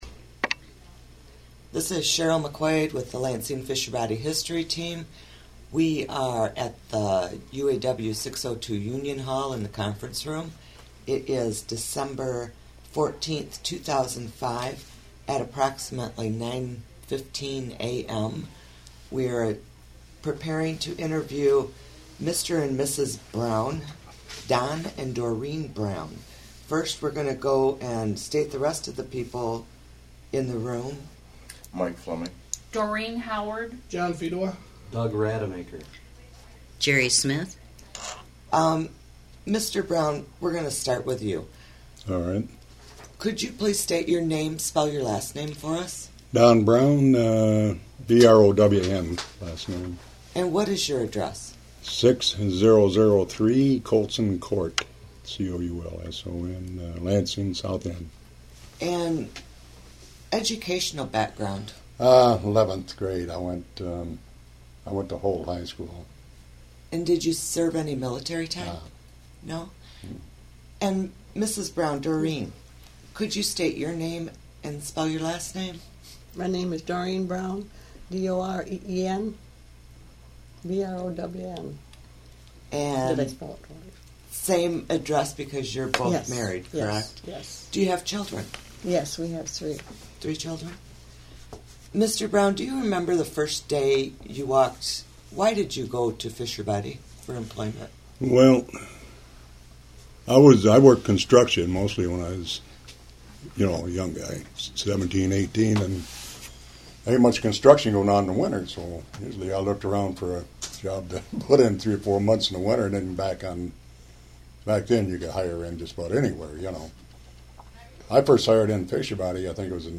United Auto Workers Local 602/General Motors Oral History Project